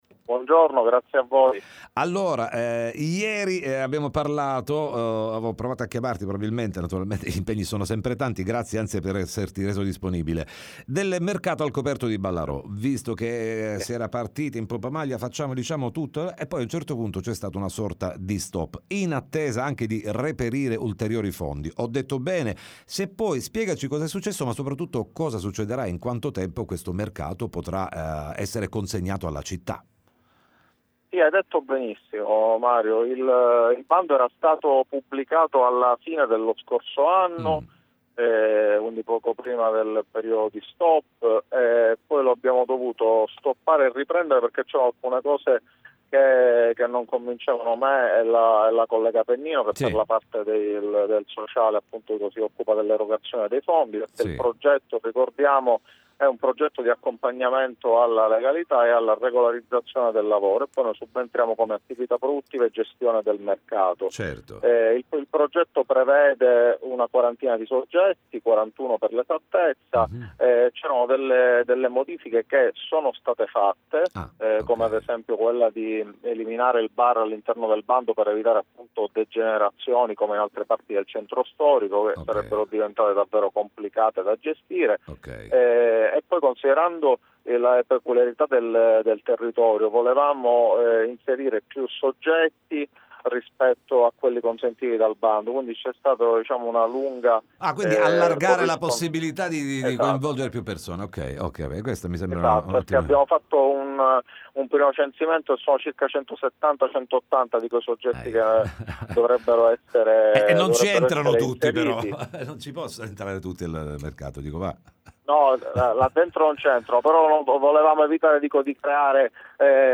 TM Intervista